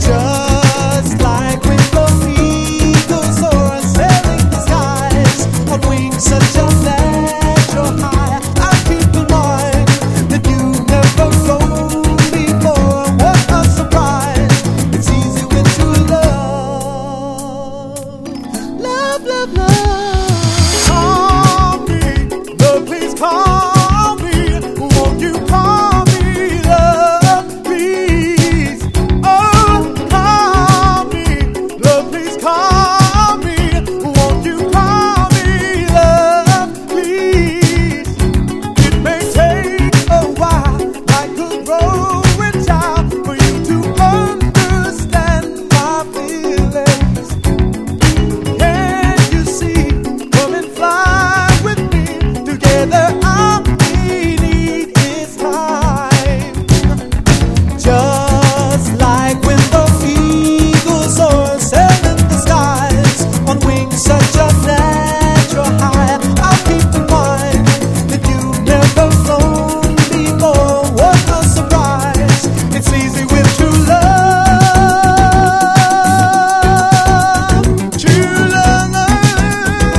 DISCO FUNK
濃厚なファンキー・ディスコ満載の2ND！
軽快なギター・カッティングのディスコ・ファンク